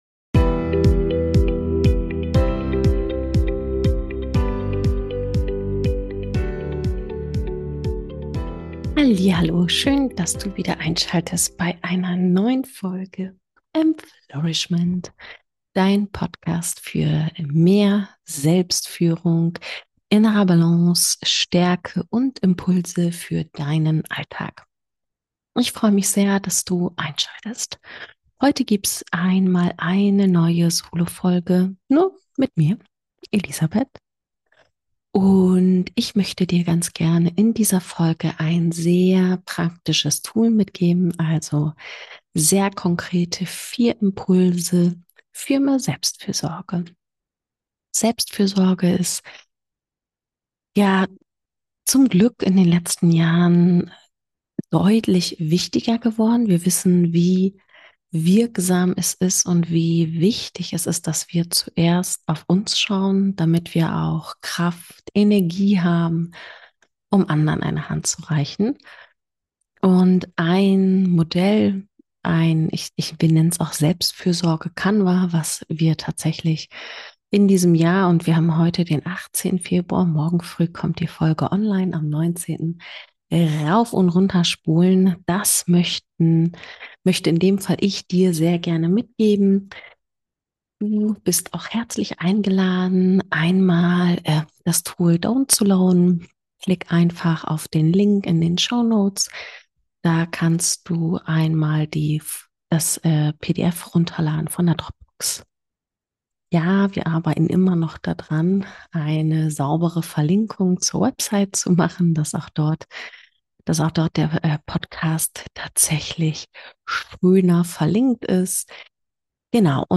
In dieser Solofolge möchte ich dir ganz konkret 4 Impulse an die Hand geben, um deine Selbstfürsorge zu stärken.